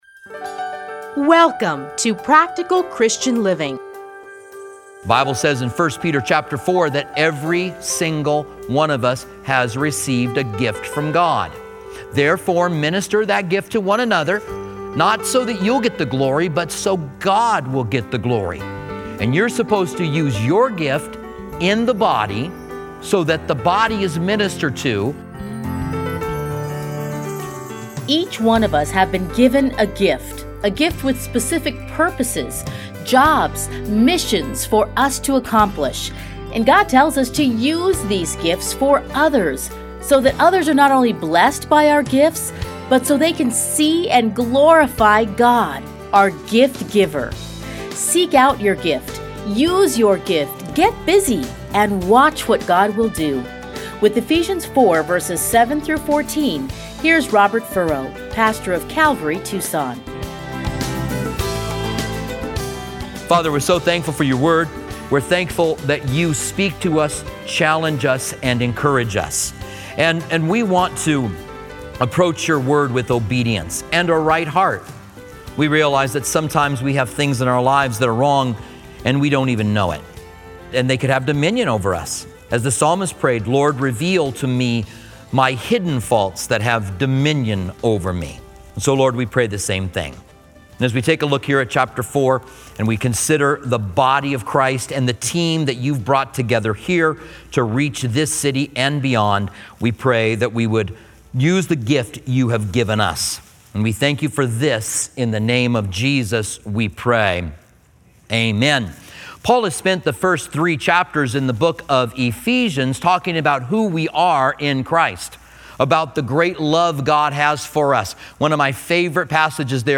Listen here to his commentary on Ephesians.